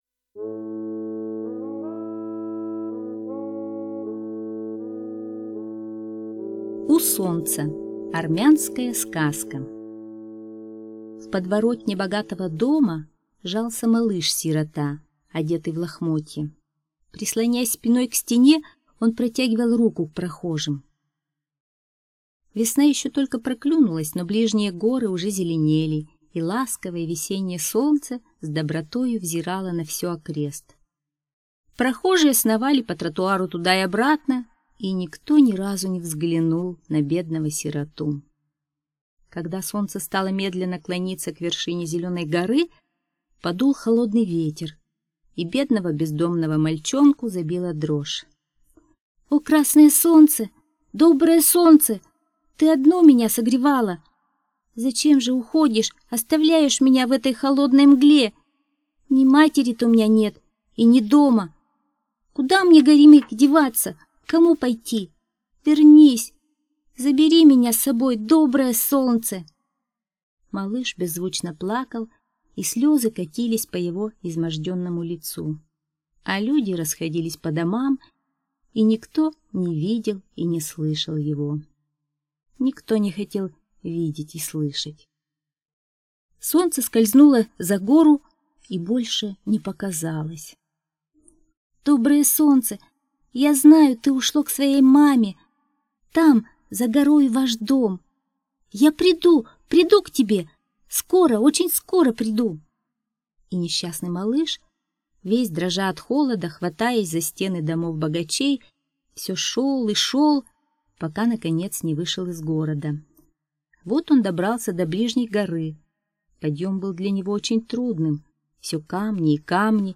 У солнца - армянская аудиосказка - слушать онлайн